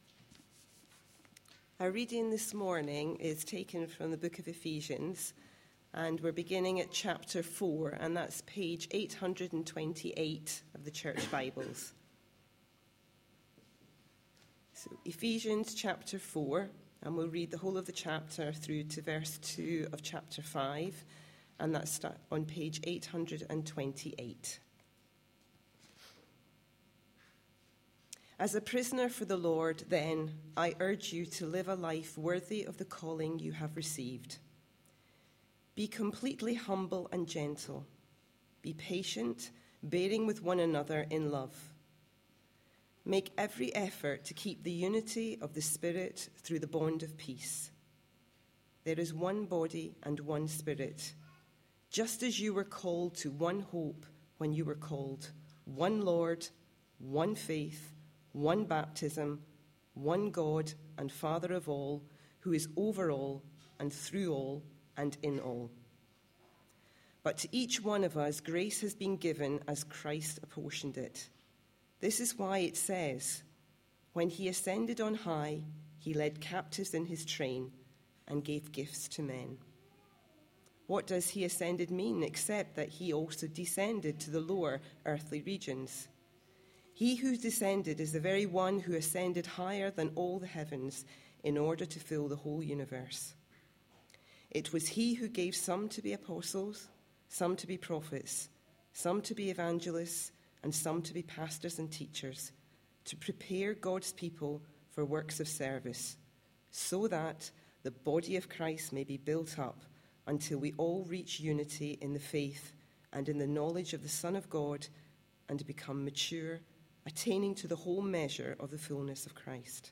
A sermon preached on 18th January, 2015, as part of our Work series.